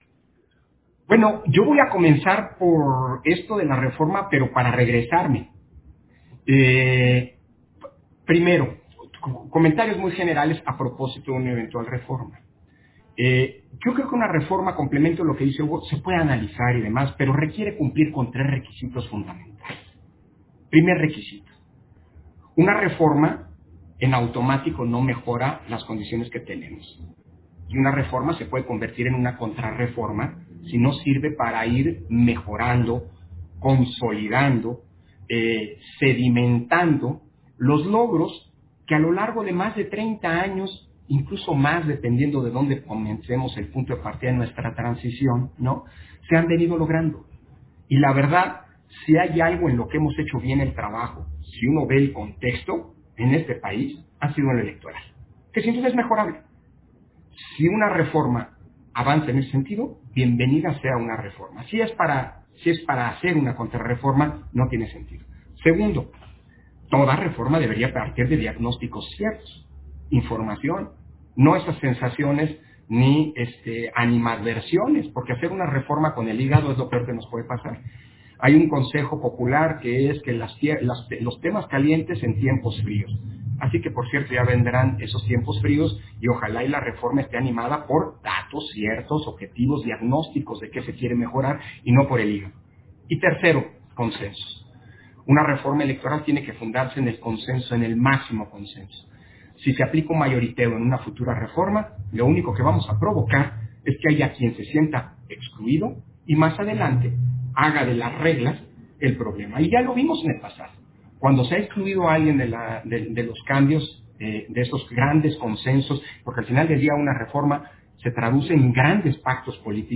240521_AUDIO_INTERVENCIÓN-CONSEJERO-PDTE.-CÓRDOVA-MESA-REDONDA-ORGANIZACIÓN-Y-RETOS-DE-LA-ELECCIÓN-6-1 - Central Electoral